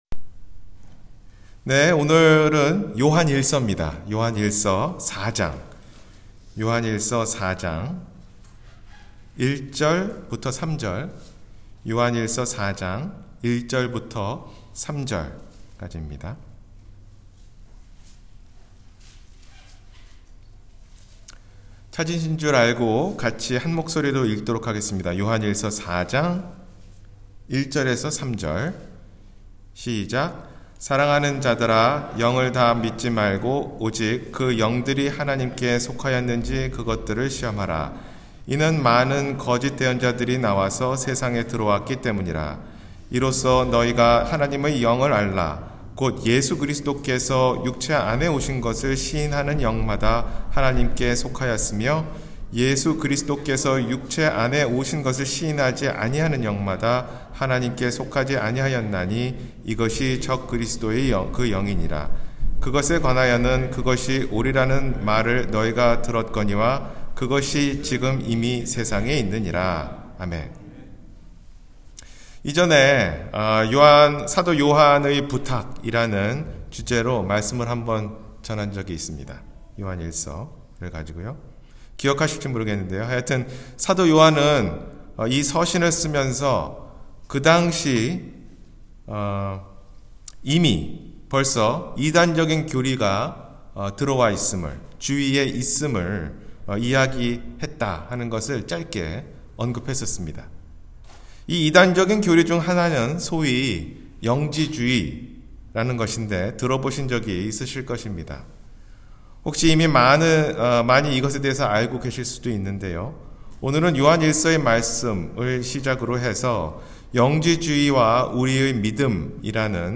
영지주의와 우리의 믿음 – 주일설교